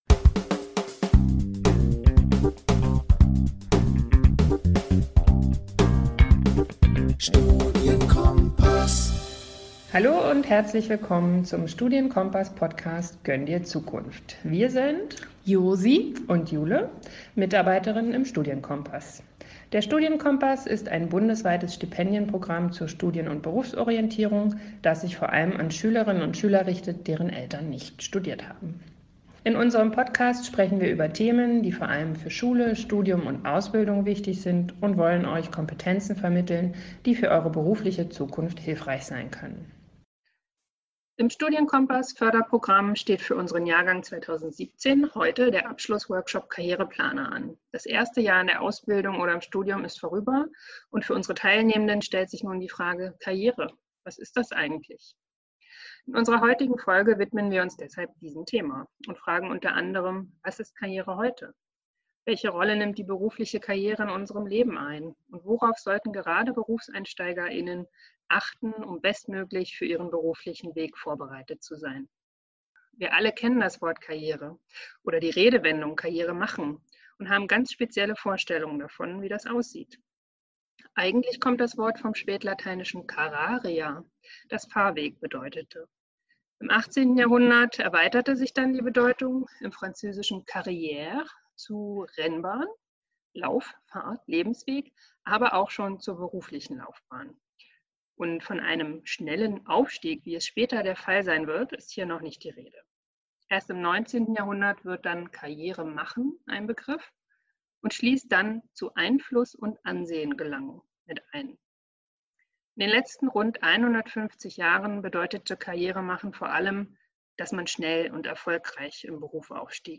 zwei spannende Frauen zu den Themen Karriere und Berufseinstieg interviewt